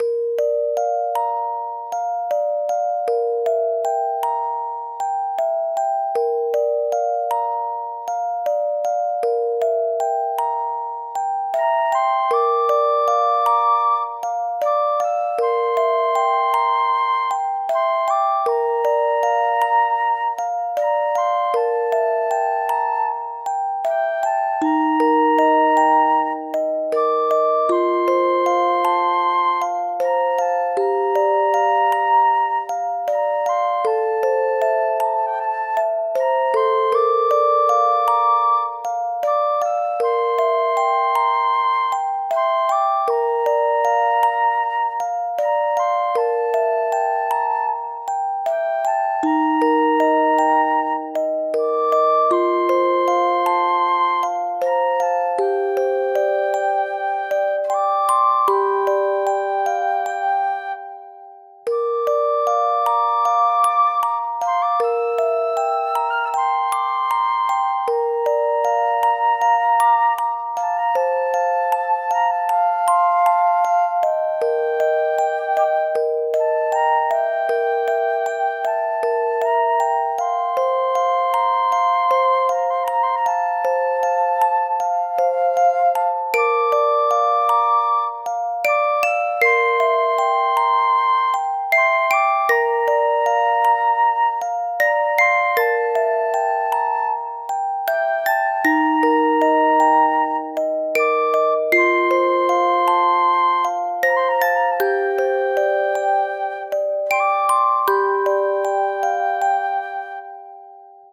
フリーBGM素材- そよ風にのせて聴こえてくる笛の音。